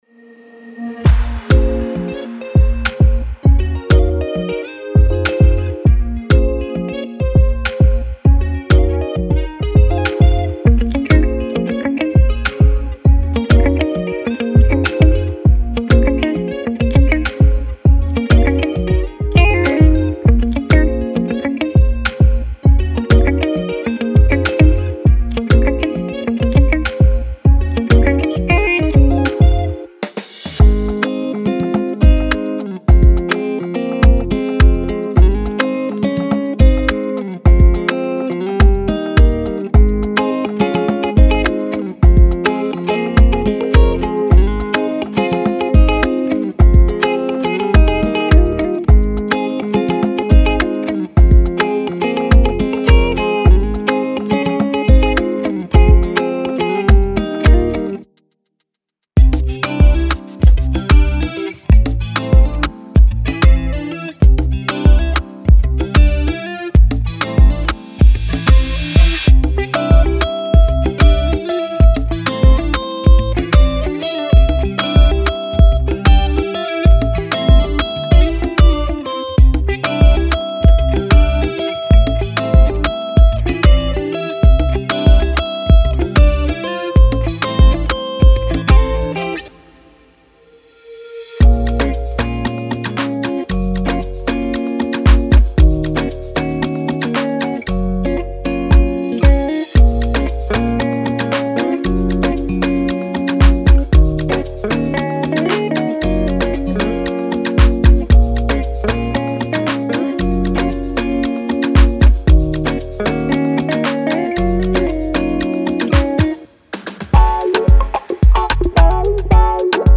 Genre:Afrobeat
デモ音源には他のサウンドも含まれていますが、これらは説明用であり本製品には含まれておりません。
120 Guitar Loops Dry